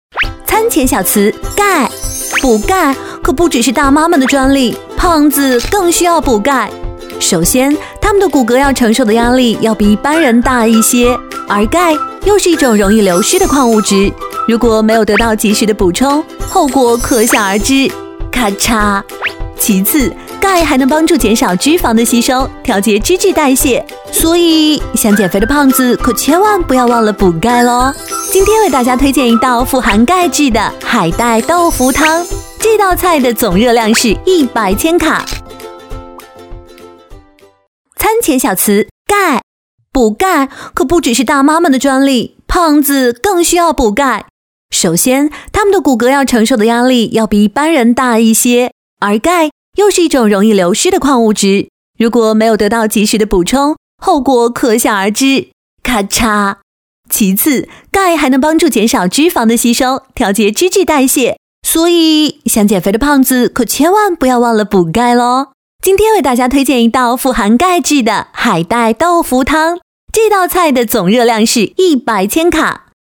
标签： 稳重
配音风格： 沉稳 稳重